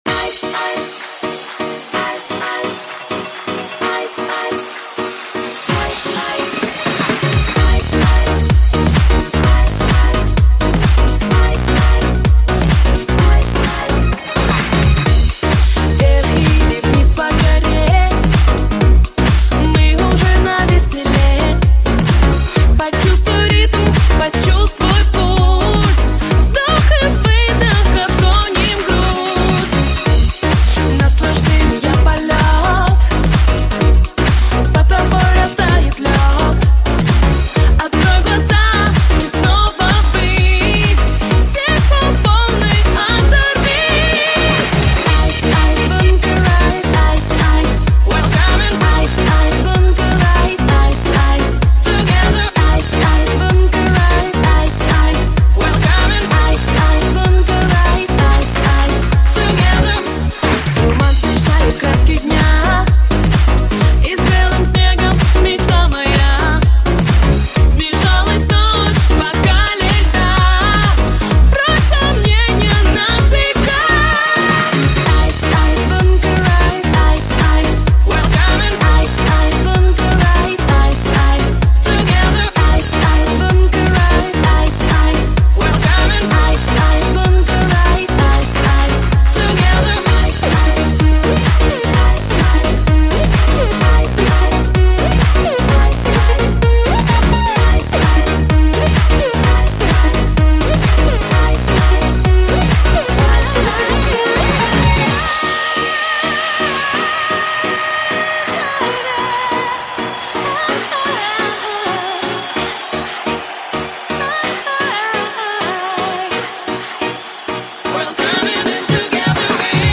Главная » Клубная музыка